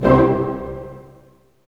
Index of /90_sSampleCDs/Roland L-CD702/VOL-1/HIT_Dynamic Orch/HIT_Orch Hit Maj
HIT ORCHMA02.wav